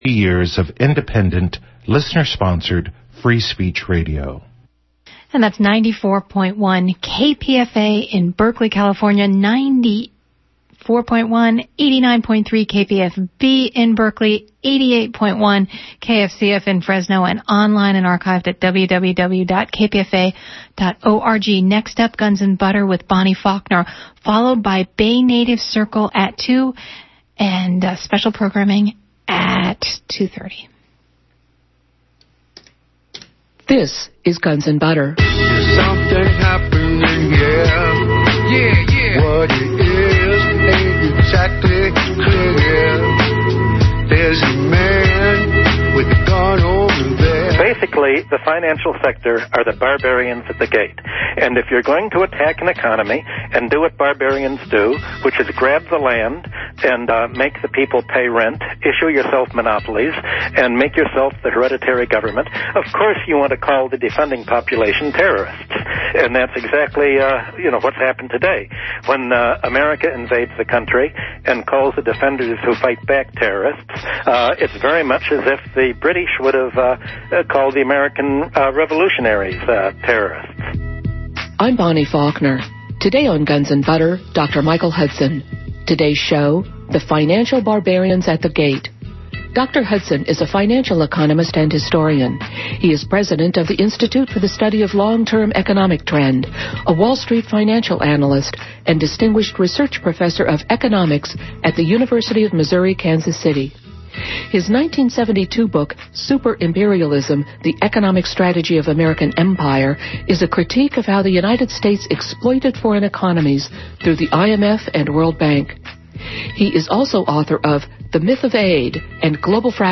The Financial Barbarians at the Gate is a Guns and Butter interview with economist / historian Michael Hudson. In it he discusses the historical takeover of the economy by the finance sector.